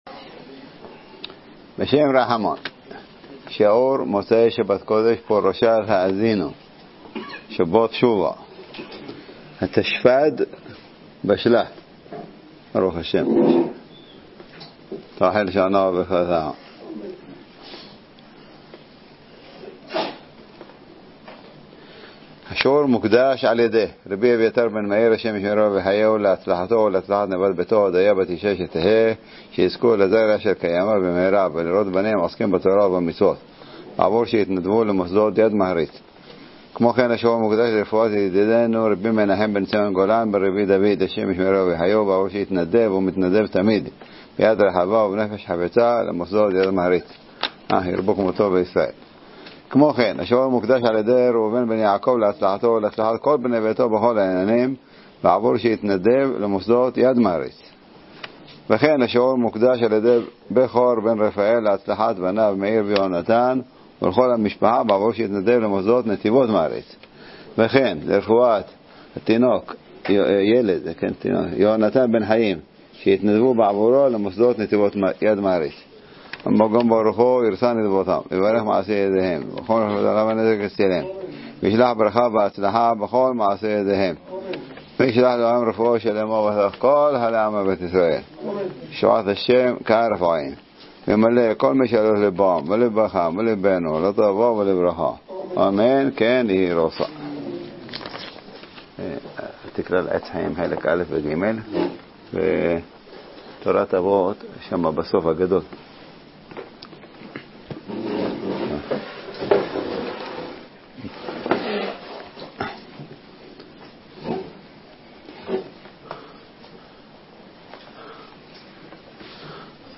מוצש"ק האזינו - דרשת שבת שובה התשפ"ד